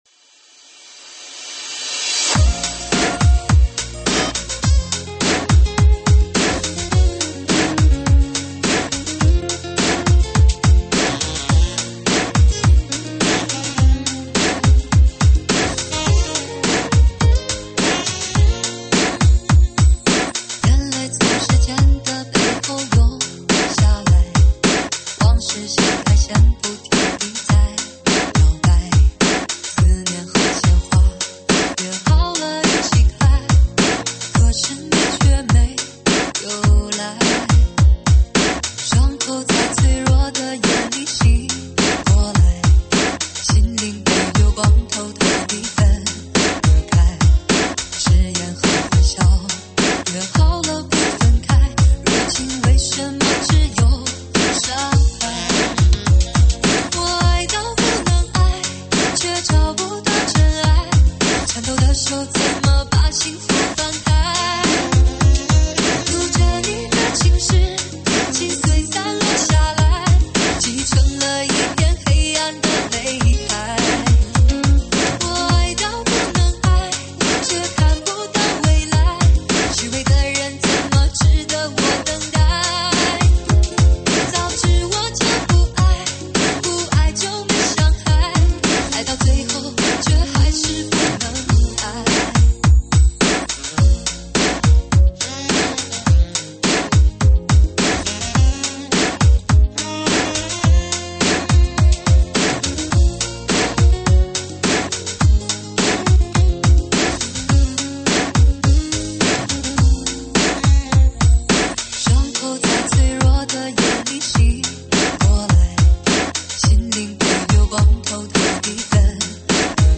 慢摇舞曲